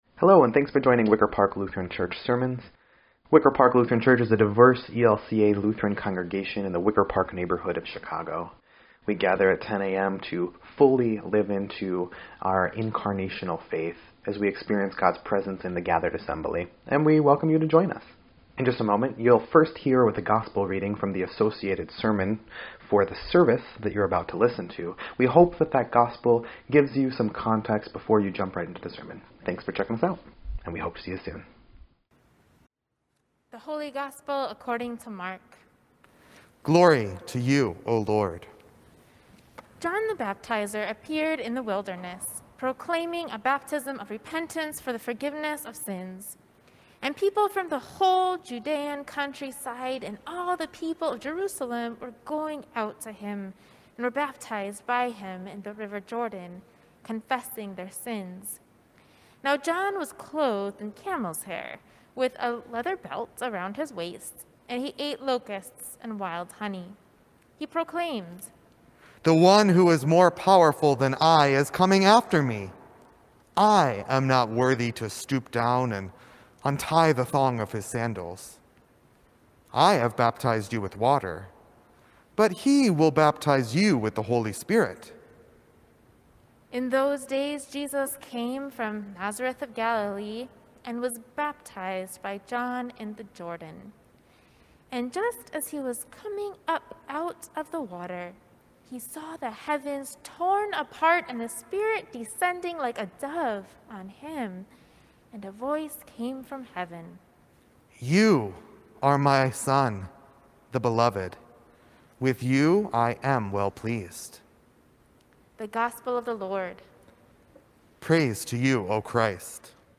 1.17.21-Sermon_EDITwav.mp3